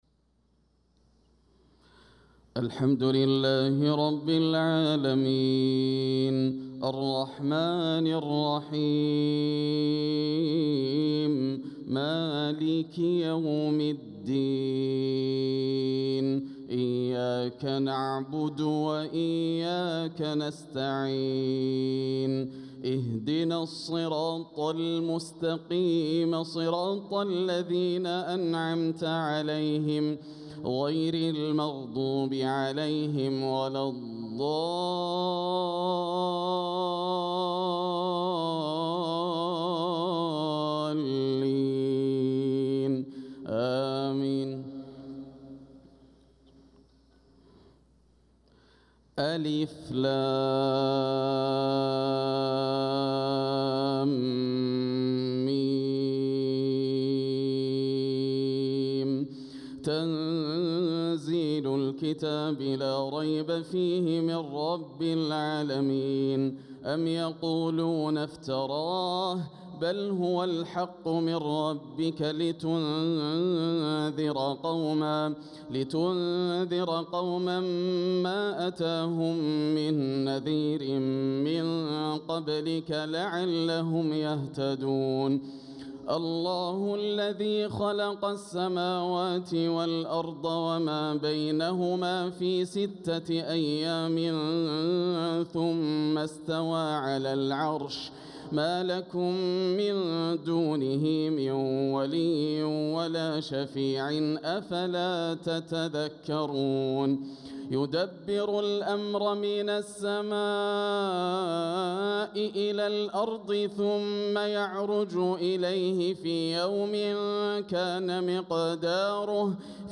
صلاة الفجر للقارئ ياسر الدوسري 5 صفر 1446 هـ
تِلَاوَات الْحَرَمَيْن .